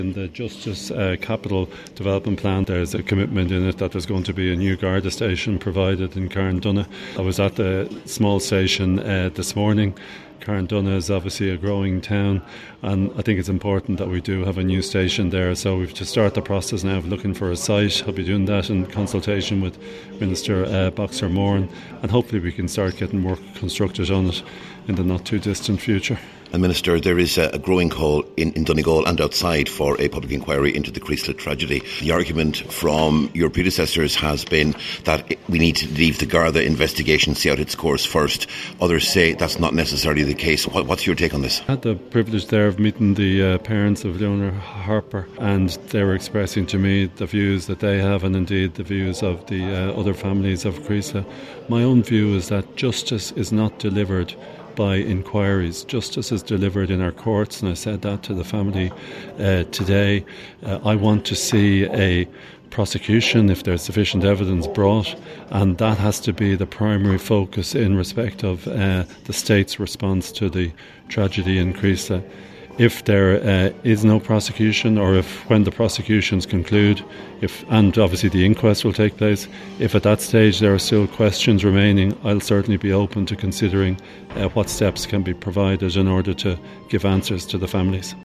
Speaking to Highland Radio News in Letterkenny this morning, he said his view is justice isn’t delivered through inquiries, and if there is a prospect of one or more prosecutions, that should be the focus.